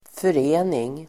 Uttal: [för'e:ning]